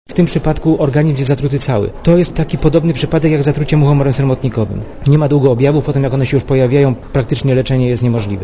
Mówi profesor